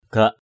/ɡ͡ɣaʔ/